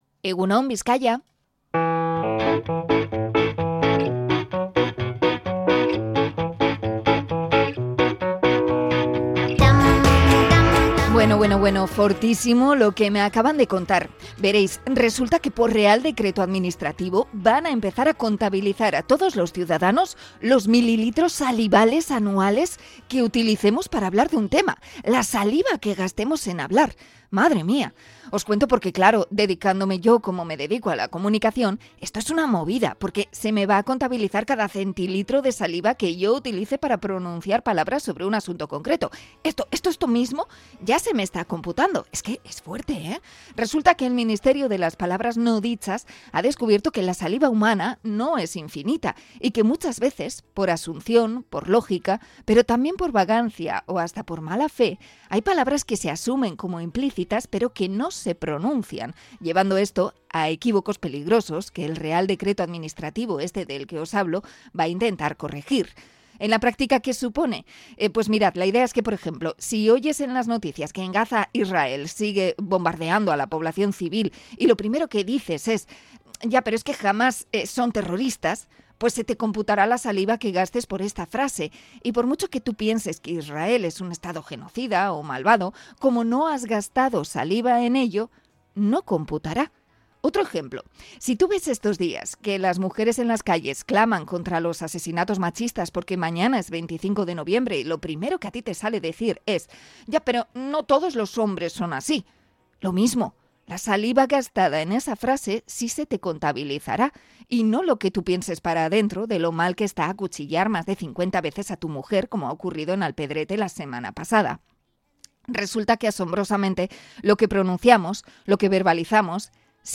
Comentario sobre la necesidad de verbalizar